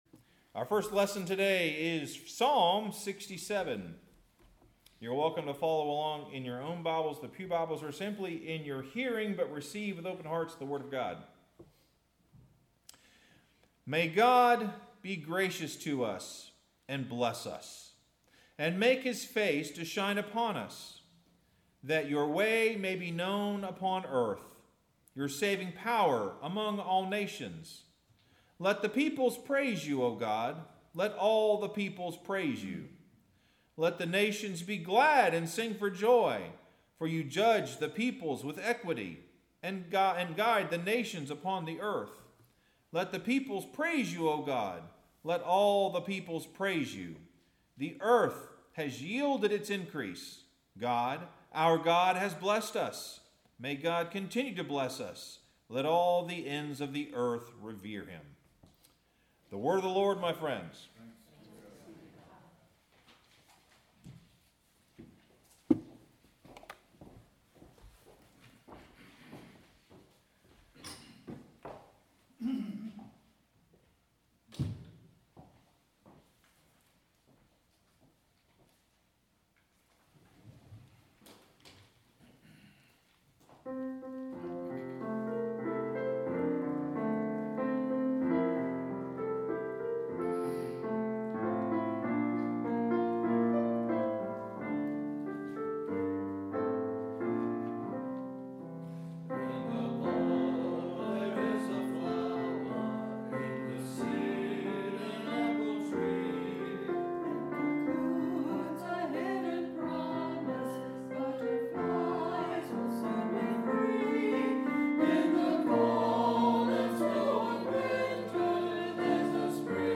Sermon – Solar Souls Psalm 67; Revelation 21:10, 22-22:5 Farmville Presbyterian Church 5/25/25 Have you ever felt the hand of God?